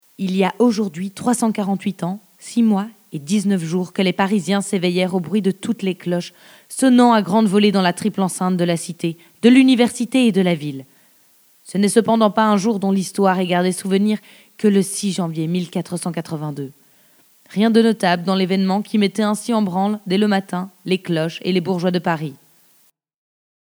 Demo Français - Notre-Dame de Paris - Victor Hugo
- Mezzo-soprano
Accent Belge